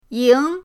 ying2.mp3